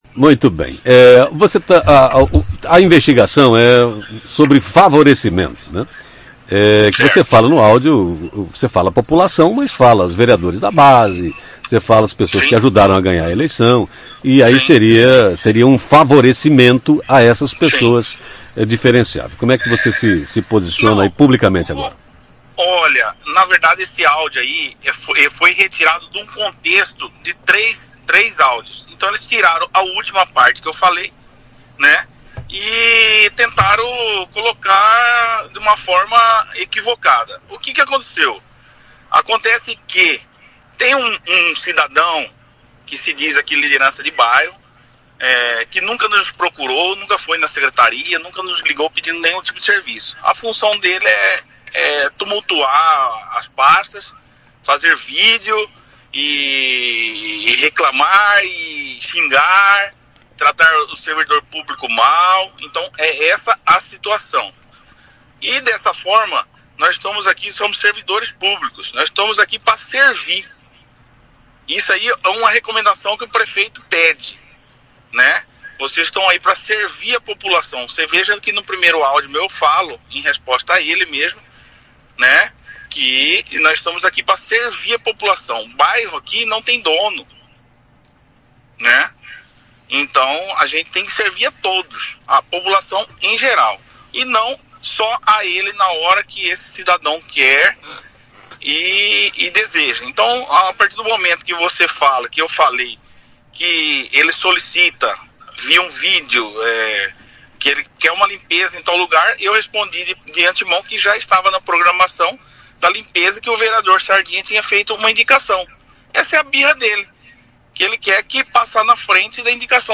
ENTREVISTA À JOVEM PAN
Em entrevista a rádio Jovem Pan o secretário de serviços públicos e mobilidade urbana de Várzea Grande, Breno Gomes, se defendeu da acusação de favorecimento ao grupo político do prefeito Kalil Baracat.
GRAVACAO-ENTREVISTA-JORNAL-DA-MANHA-JOVEM-PAN.mp3